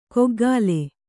♪ koggāle